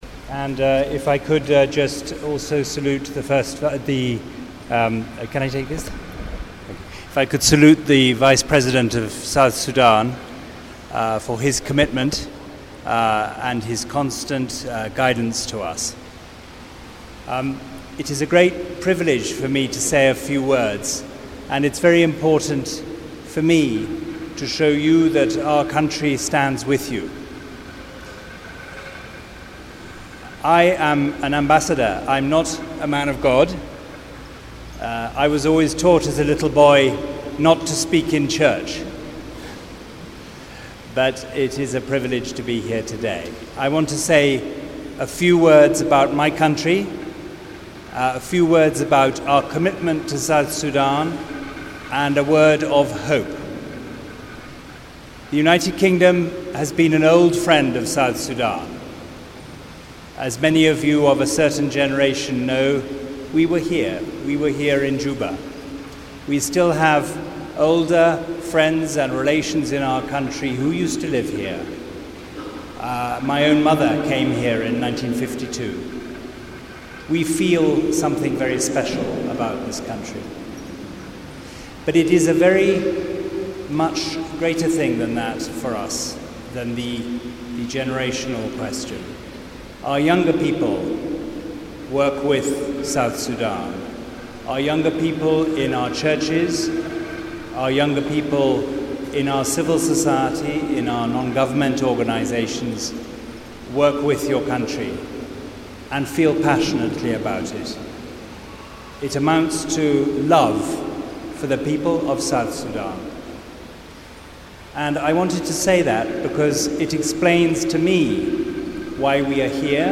to congregation at St Theresa Cathedral today.